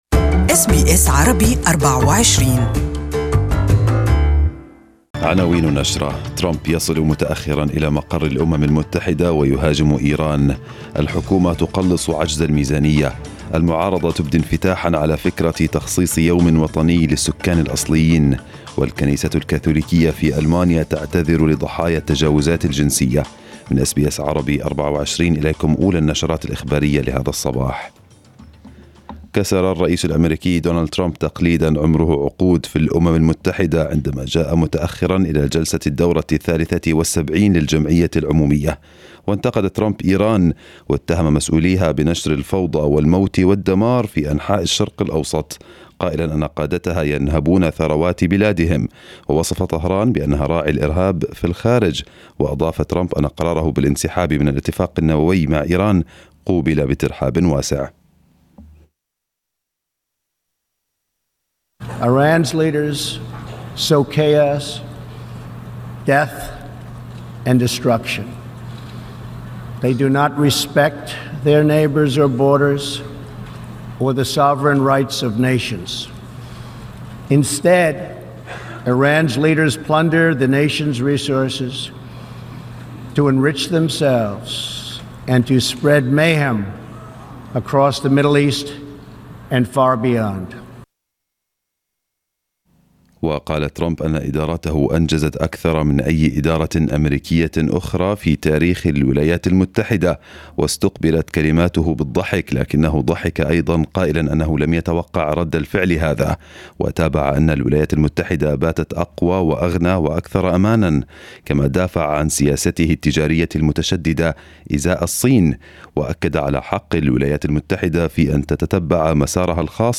First news bulletin in this morning